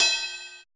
626 CUP.wav